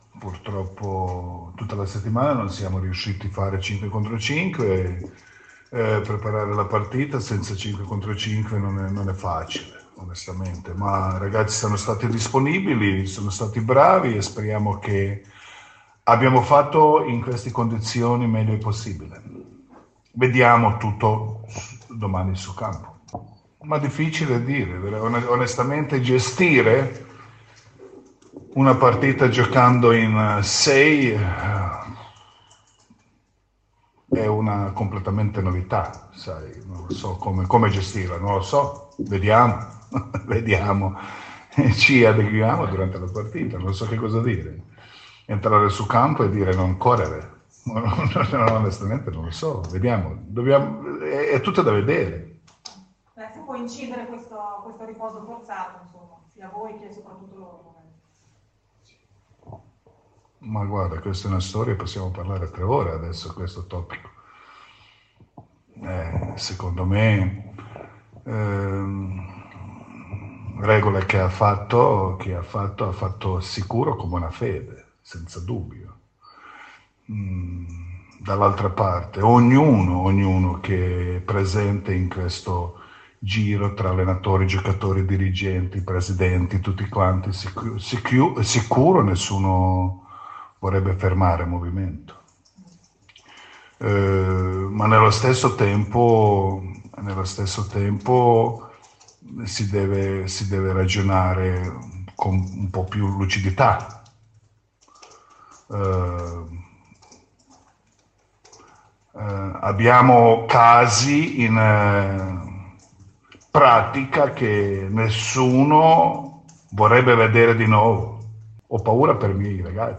Nella consueta conferenza stampa pre partita, Coach Jasmin Repesa ci parla della prossima sfida con Reggio Emilia, che attente la Vuelle.